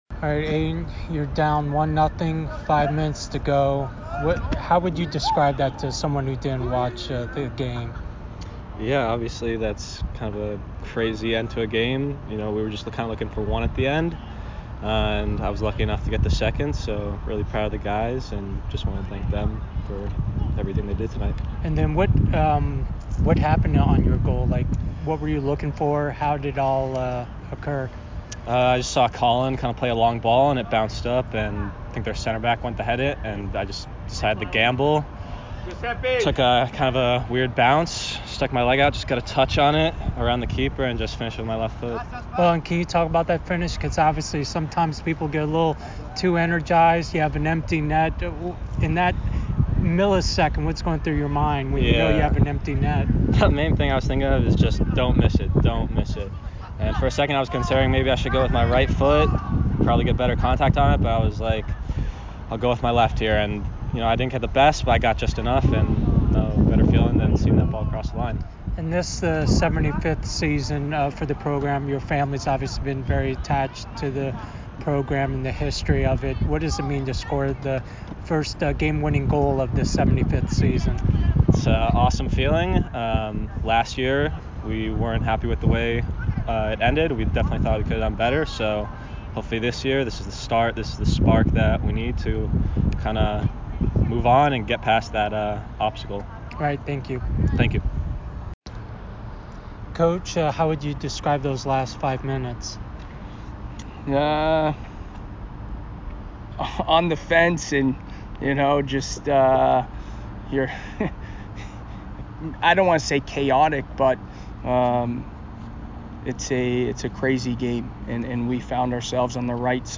Men's Soccer / UAlbany Postgame Interview (8-24-23)